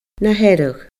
Na Hearadh /nə hɛrəɣ/